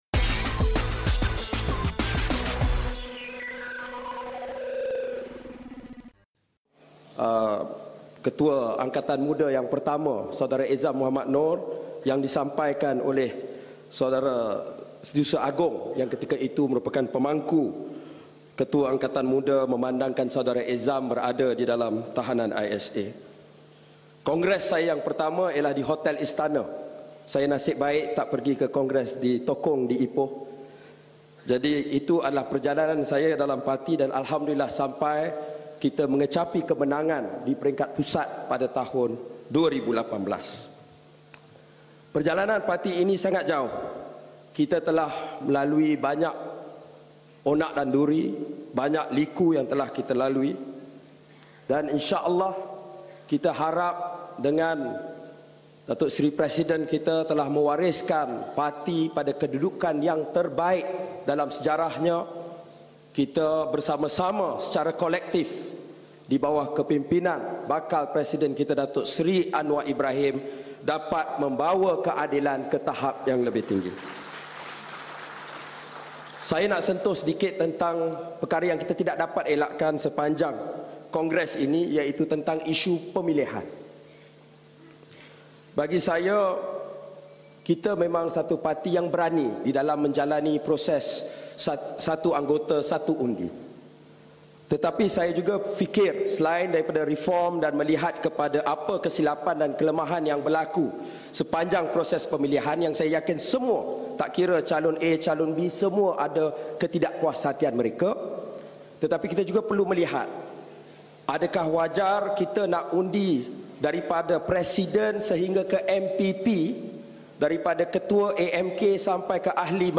Ucapan penggulungan oleh Ketua Angkatan Muda Keadilan, Nik Nazmi Nik Ahmad yang berlangsung di Shah Alam.